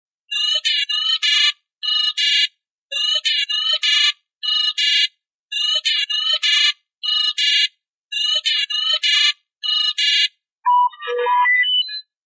Ringing tone from Deckard's Ground car
Not sure if I opened this in the right section, but I wanted to have the ringing tone sound of when Deckard calls JF's apartment from his Ground car as my ringtone.